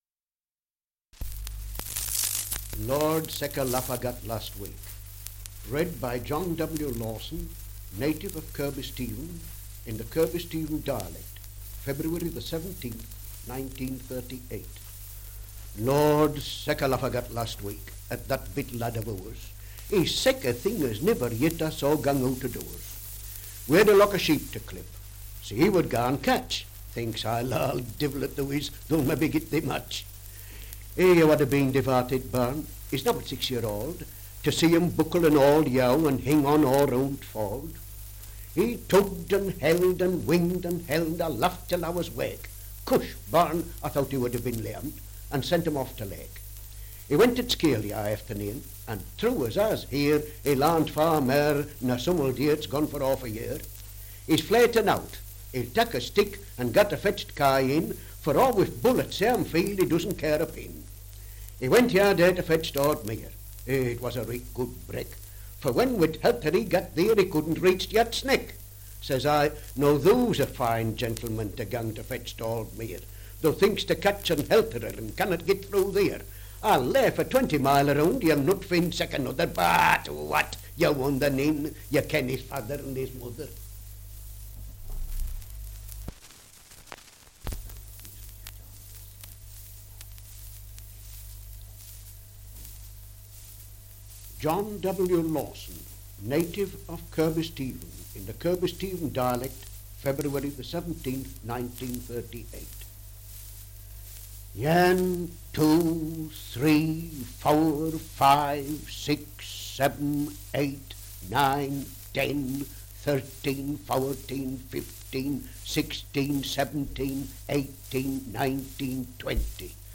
Dialect recording in Kirkby Stephen, Westmorland
78 r.p.m., cellulose nitrate on aluminium
English Language - Dialects